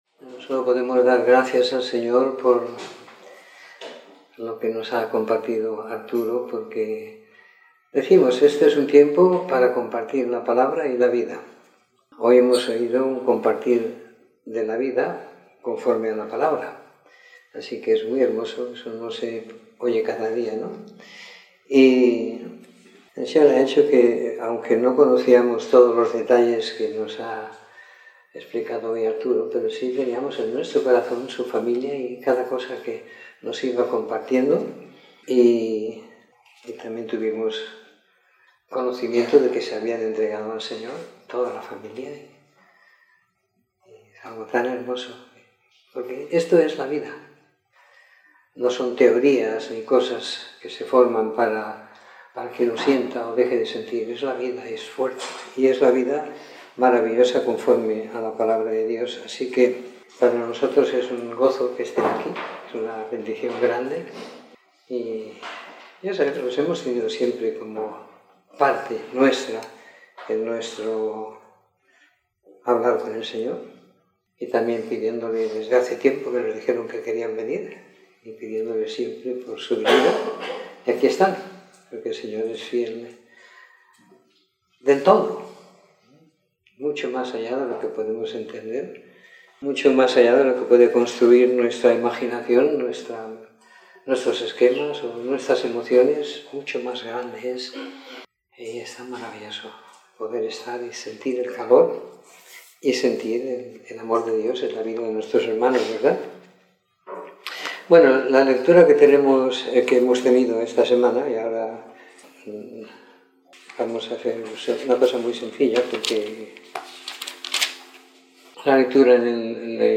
Comentario en el libro de Ezequiel del capítulo 1 al 32 siguiendo la lectura programada para cada semana del año que tenemos en la congregación en Sant Pere de Ribes.